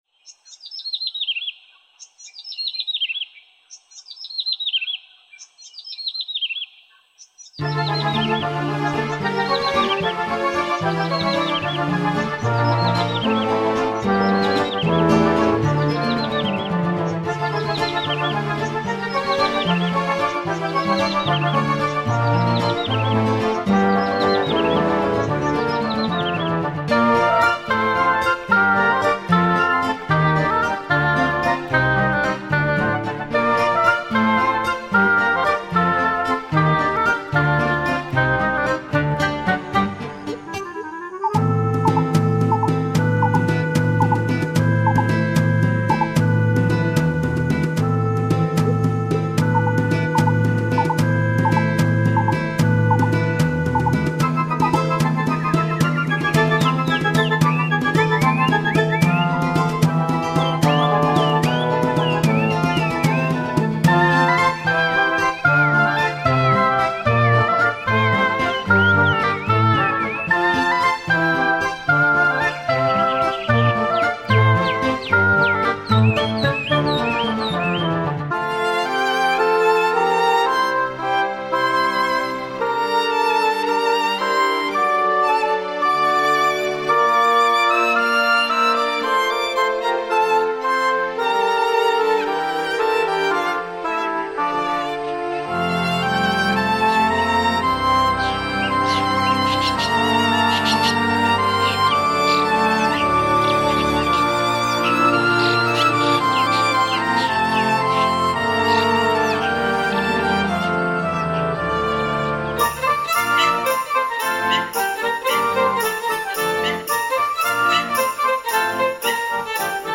背景音乐